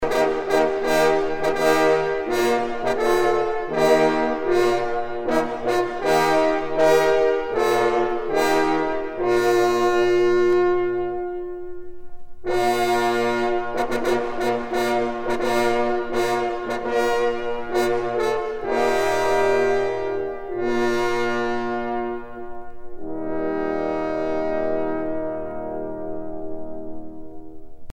trompe - Fanfares et fantaisies de concert
circonstance : vénerie